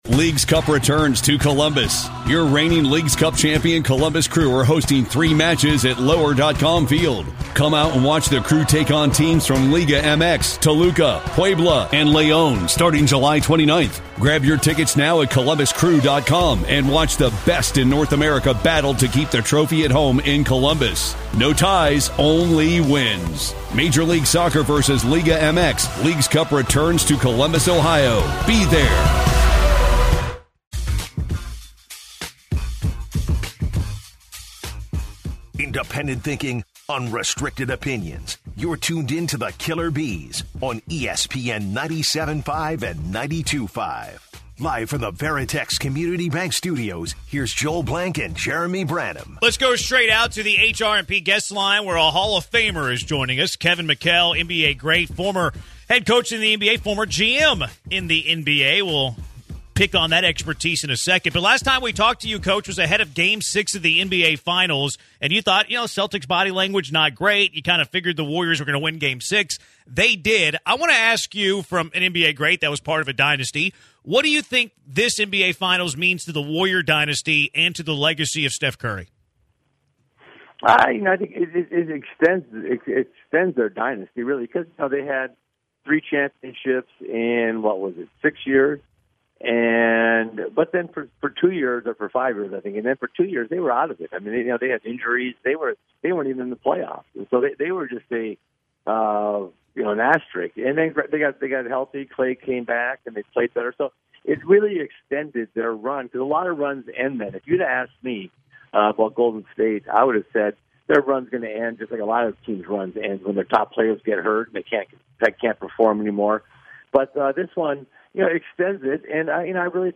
NBA Great Kevin McHale joins The Killer B's to talk NBA Draft.